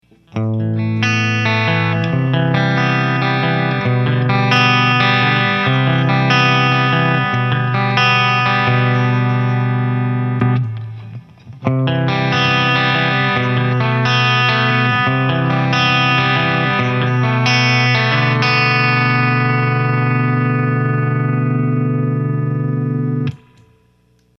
ロングサスティーン！
MXR DYNACOMPのサンプルサウンドです。
EMG89とJCM２０００とZW-44です。
クリーンサウンド